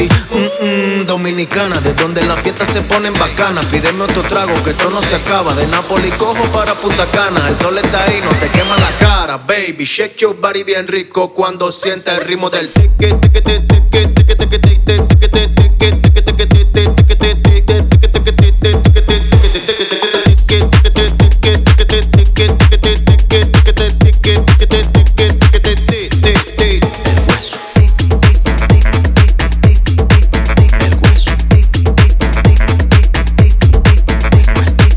Dance House
Жанр: Танцевальные / Хаус